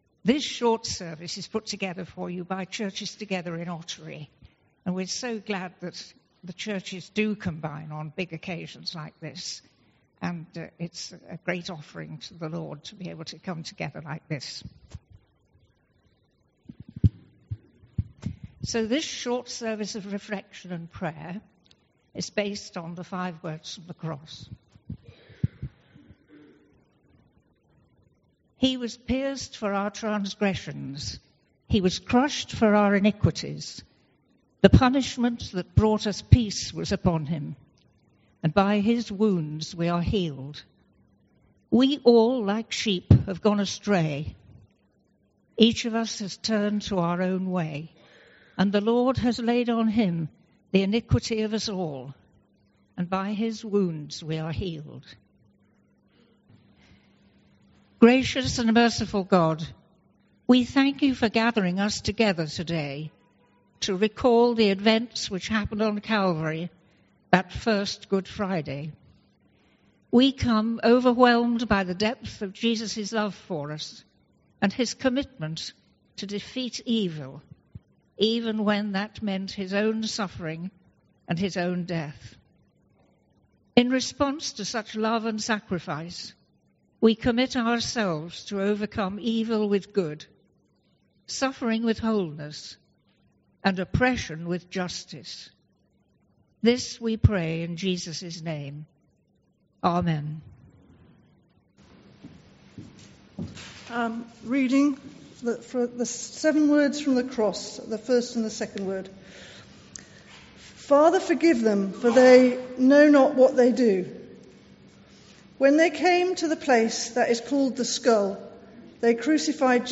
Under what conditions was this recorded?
Volunteers and church members will carried a cross throughout the streets, returning for a shared service at Ottery St Mary United Reformed Church at 11am. An audio recording of the shared service is available.